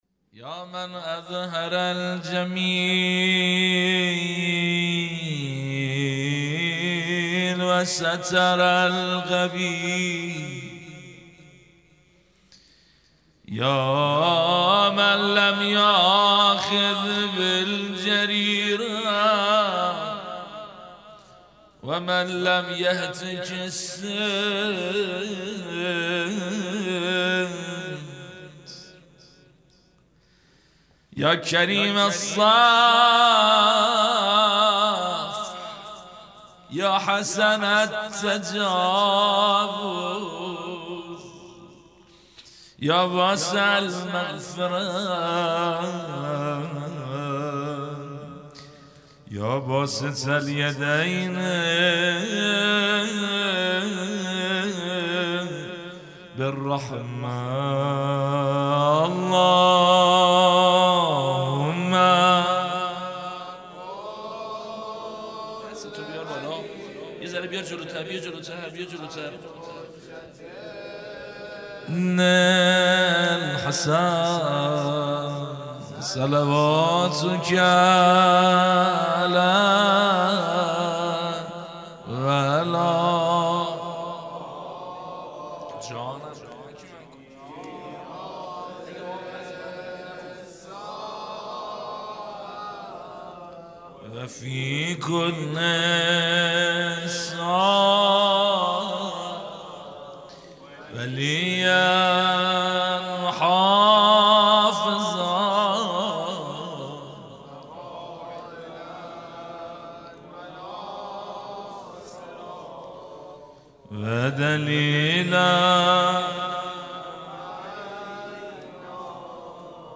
ولادت امام حسن عسکری (ع) | ۲۴ آذر ۱۳۹۷